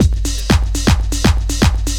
Milky Beat 4_121.wav